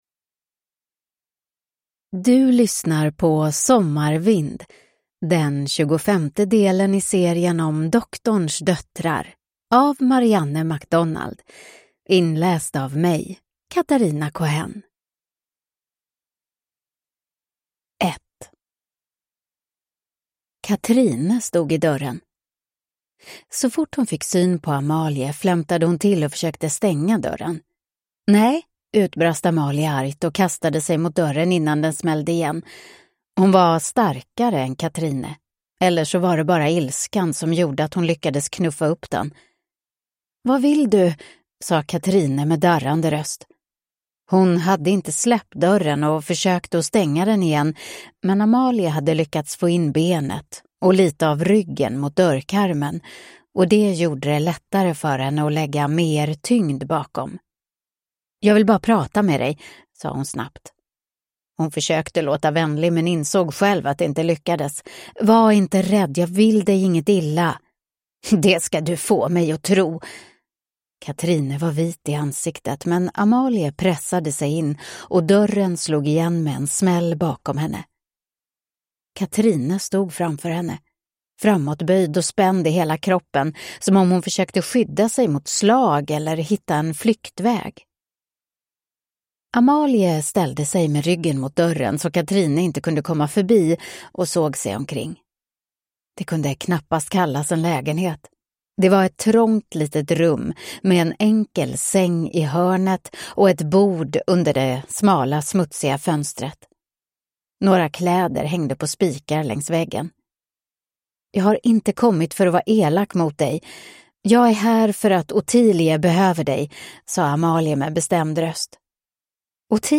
Sommarvind (ljudbok) av Marianne MacDonald